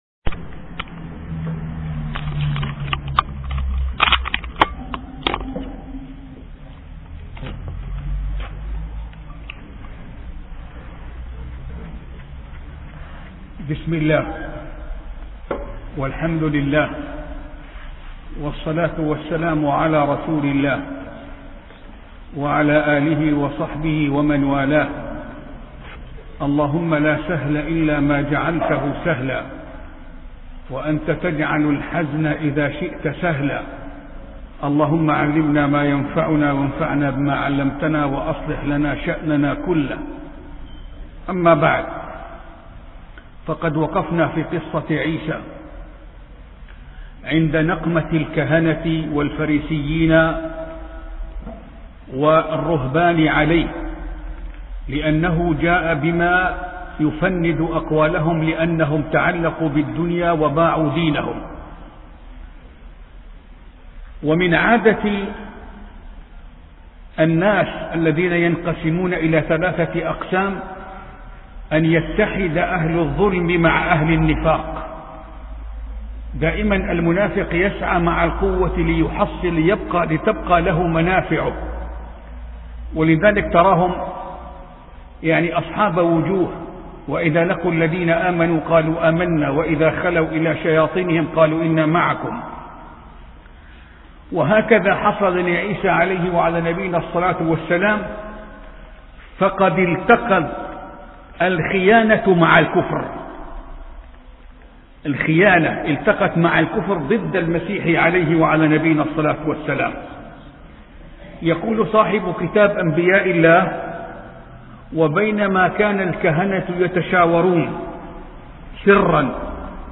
سلسلة محاطرات لشيخ